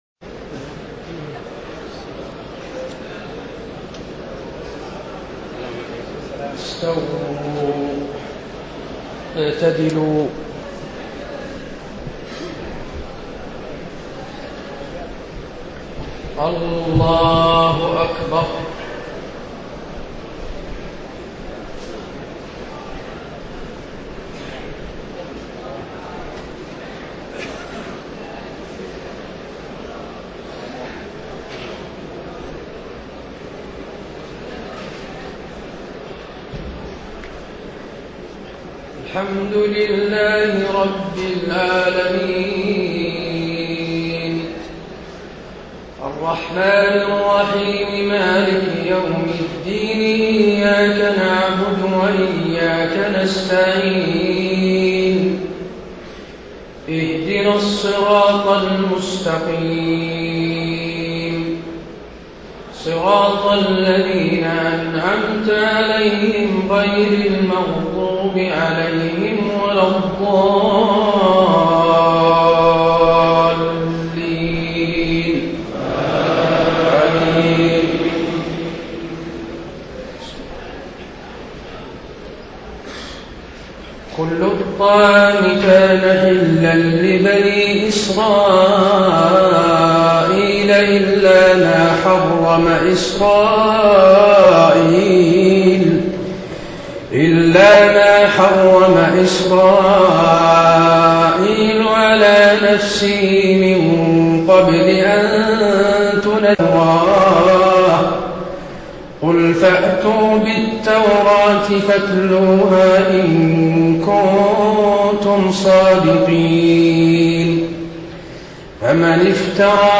تهجد ليلة 24 رمضان 1433هـ من سورة آل عمران (93-185) Tahajjud 24 st night Ramadan 1433H from Surah Aal-i-Imraan > تراويح الحرم النبوي عام 1433 🕌 > التراويح - تلاوات الحرمين